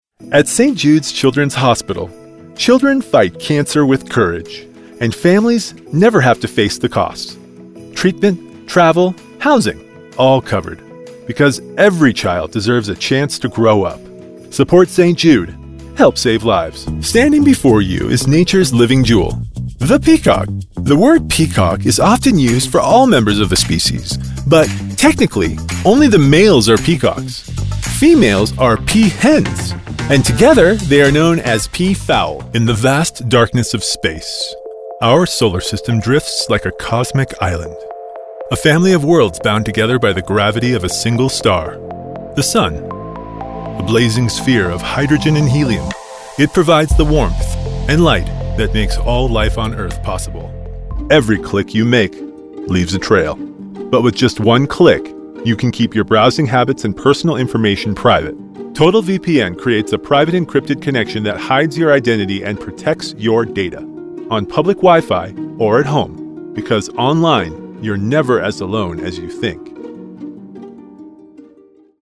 Warm, trustworthy narration. Bold commercial reads. A clear instructional voice.
Confident, Warm, and Instantly Relatable -- Male Actor Commercial Demo
I work from a professional, broadcast-quality home studio, allowing for fast turnaround times and the flexibility to support both short-notice requests and long-term projects.